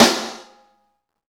SNR XEXTS10L.wav